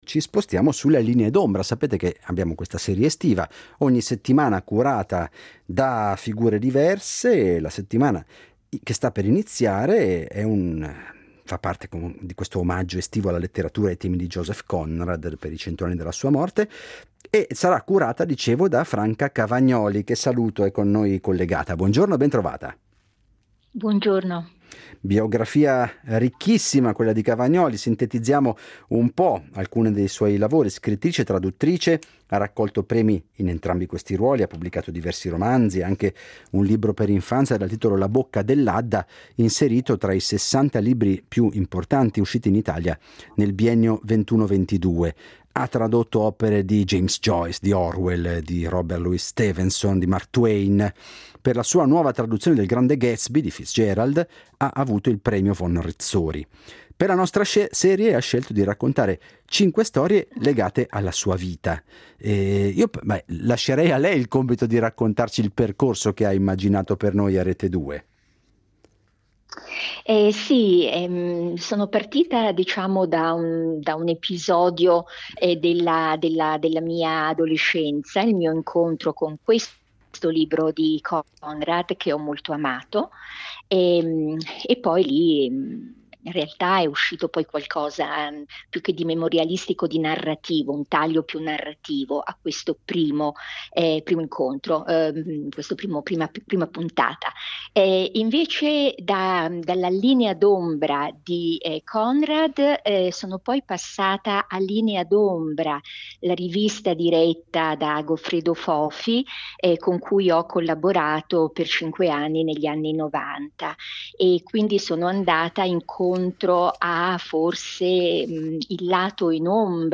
Intervista alla scrittrice e traduttrice